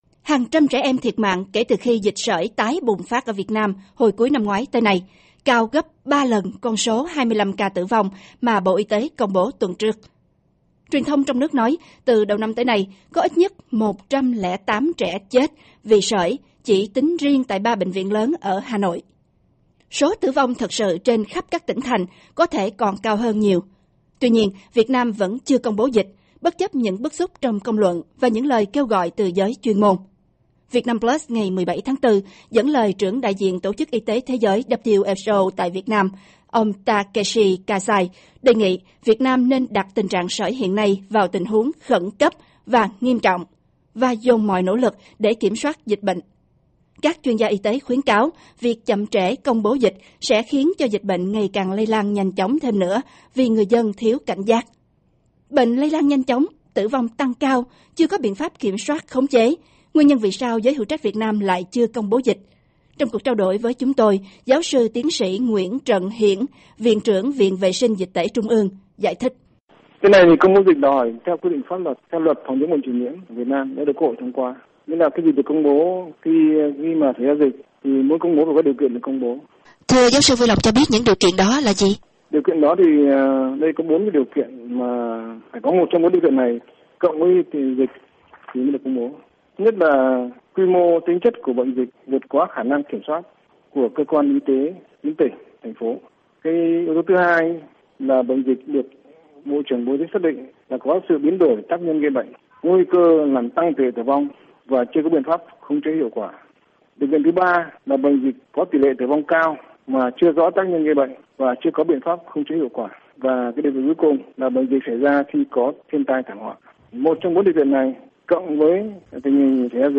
Trong cuộc trao đổi